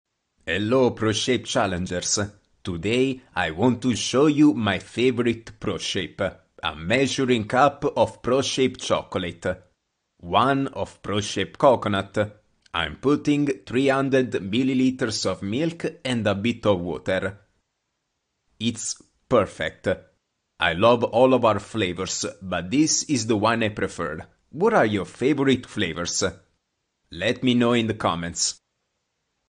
当前位置：首页> 样音试听 >优选合集 >外语配音合集 >小语种配音